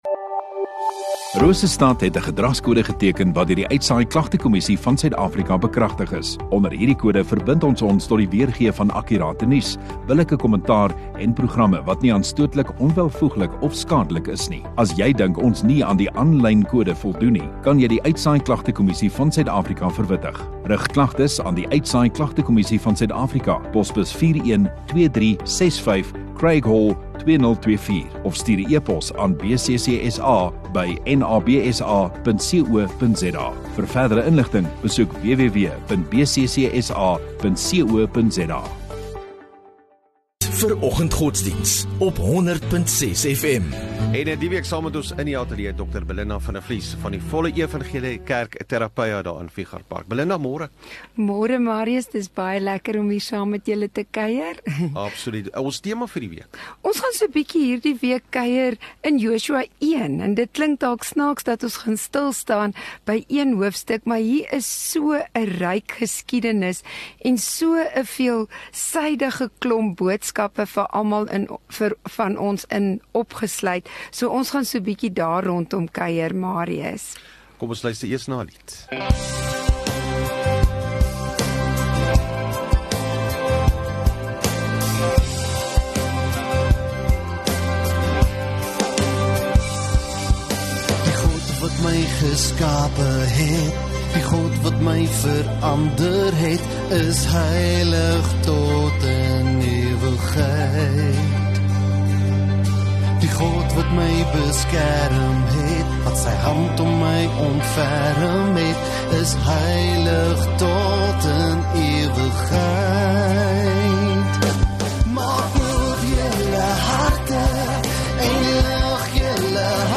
25 Nov Maandag Oggenddiens